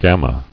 [gam·ma]